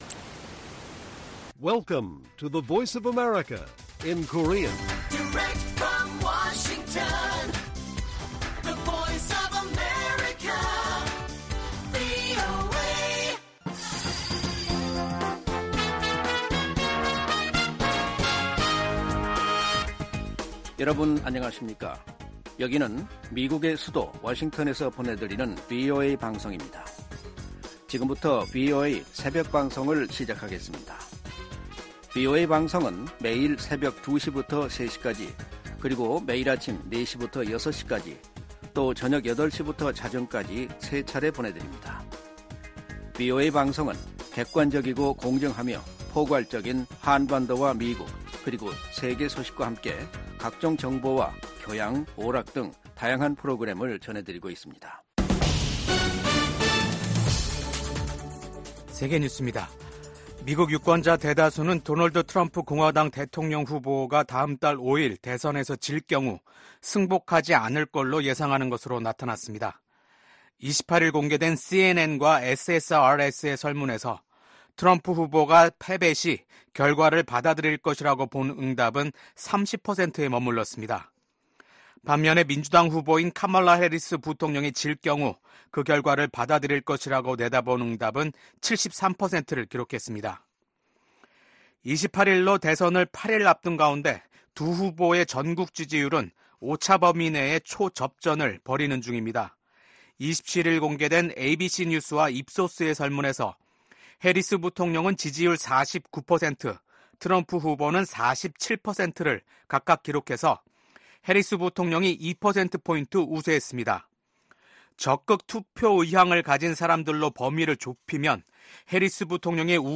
VOA 한국어 '출발 뉴스 쇼', 2024년 10월 29일 방송입니다. 이시바 시게루 총리가 취임하고 한 달도 안 돼 치른 일본 중의원 선거에서 여당이 과반 의석 수성에 실패했습니다. 이란 정부가 모든 가용한 수단을 써서 이스라엘의 공격에 대응할 것이라고 경고했습니다.